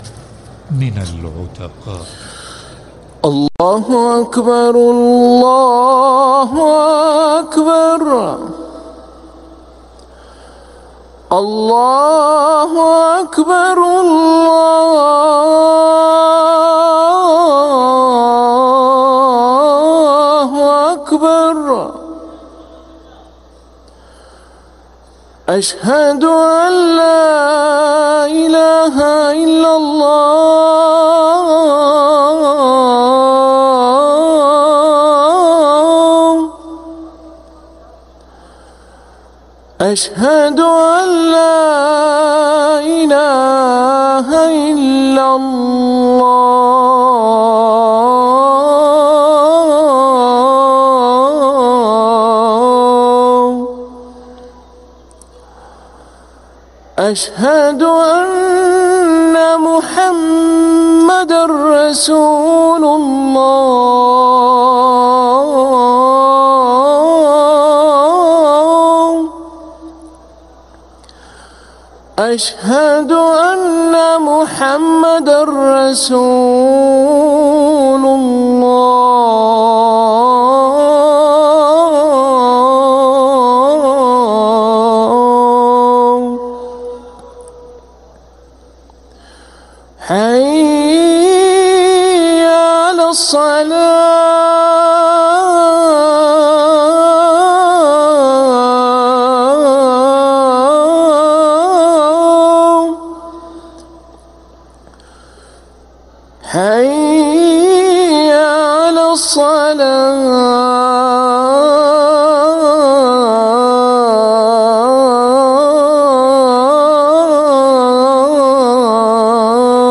أذان العشاء
ركن الأذان